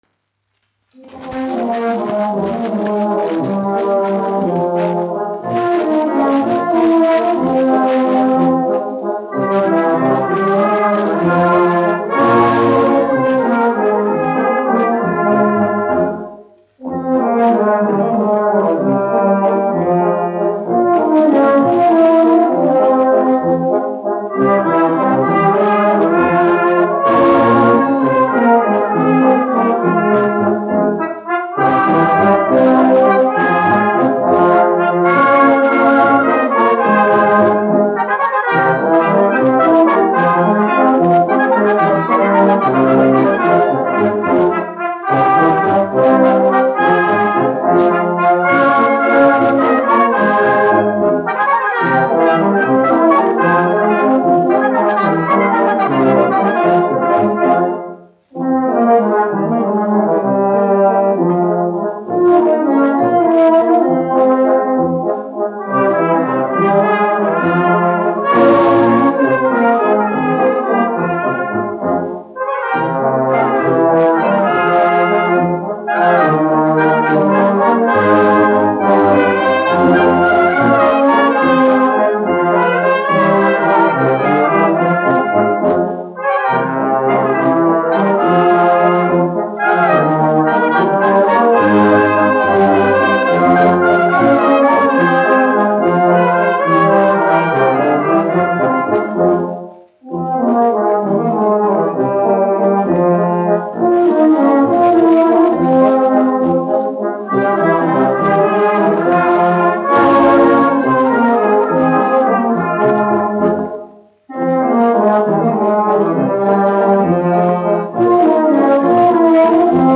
1 skpl. : analogs, 78 apgr/min, mono ; 25 cm
Pūtēju orķestra mūzika
Latvijas vēsturiskie šellaka skaņuplašu ieraksti (Kolekcija)